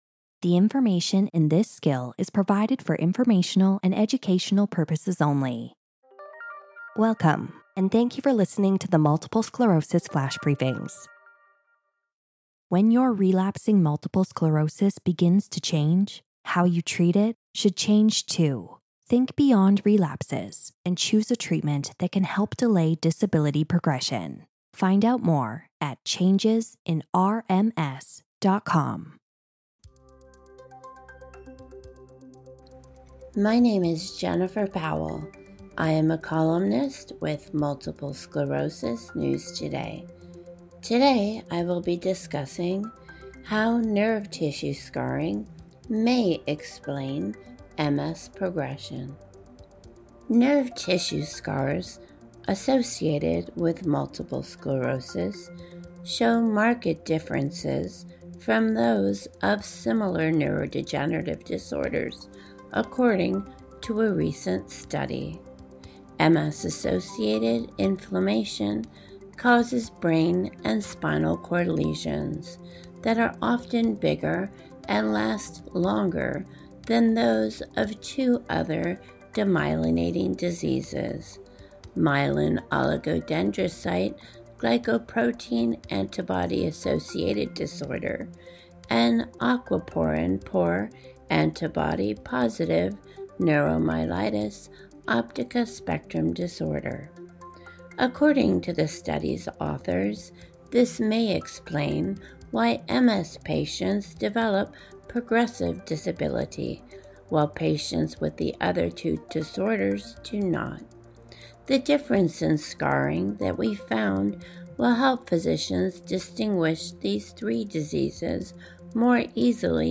reads the column